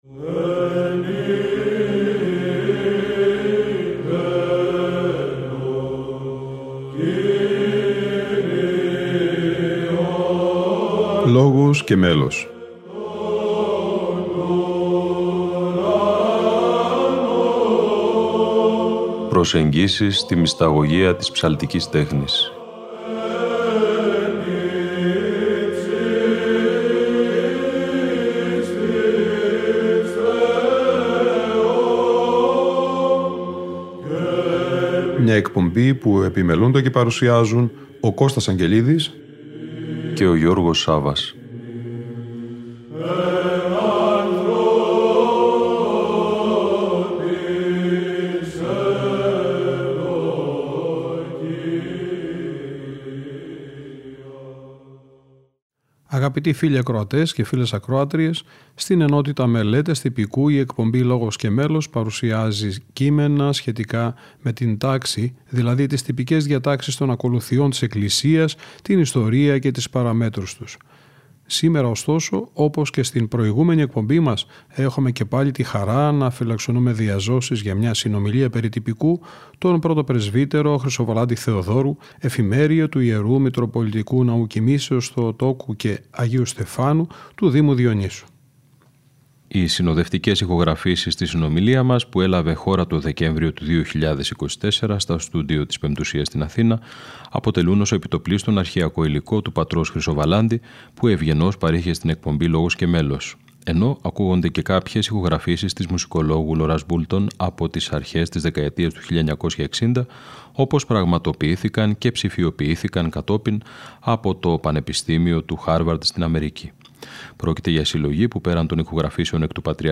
Μελέτες Τυπικού - Μια συνομιλία